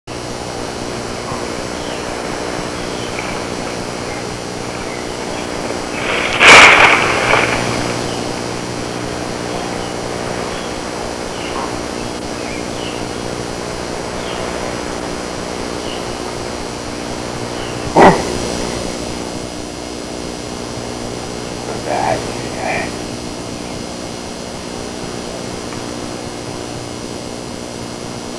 Whisperer_loud.wav